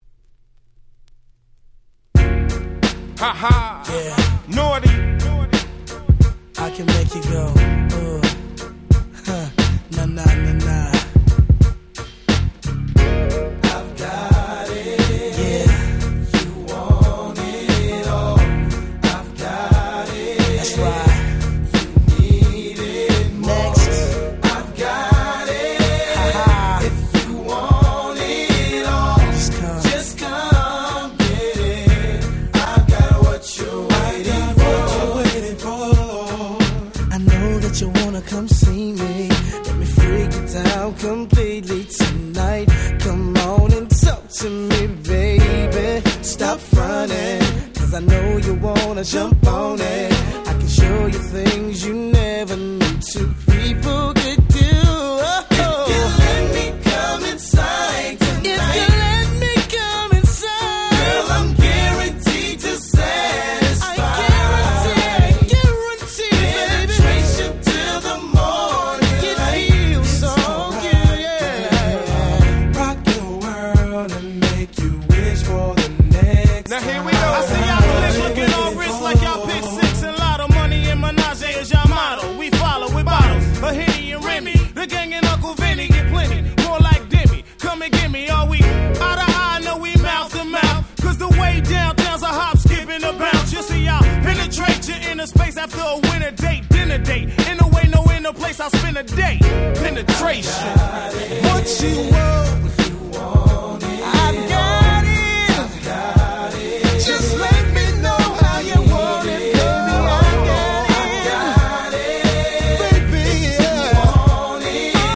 97' Smash Hit Hip Hop !!